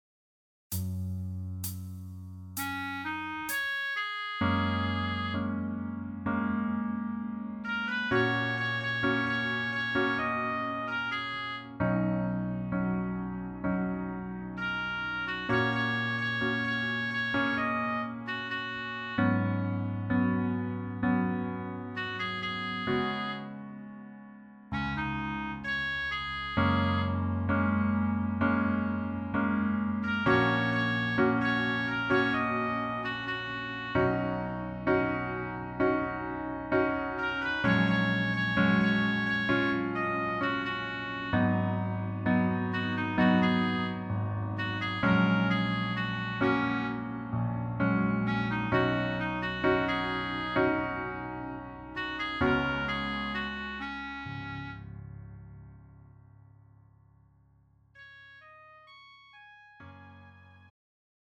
음정 -1키 4:34
장르 pop 구분 Pro MR
Pro MR은 공연, 축가, 전문 커버 등에 적합한 고음질 반주입니다.